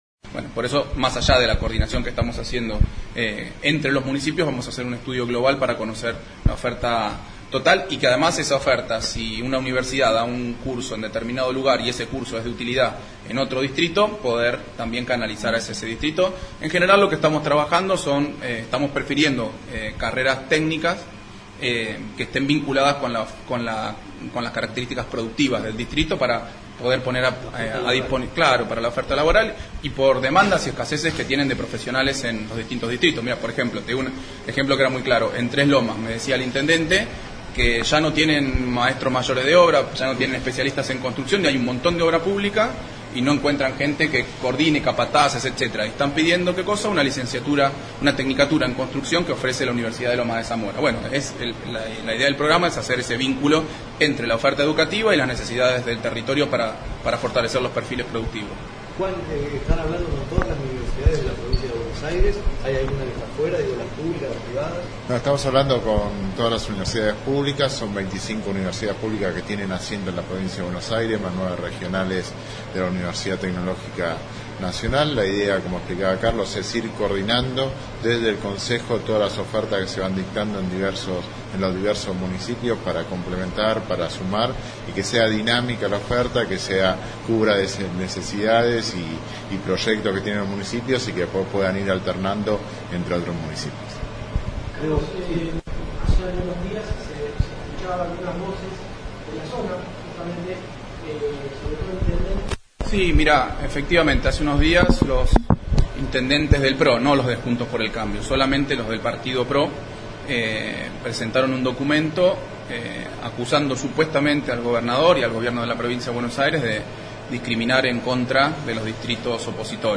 Conferencia de prensa Carlos Bianco